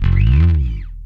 E1 6 F.BASS.wav